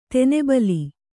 ♪ tene bali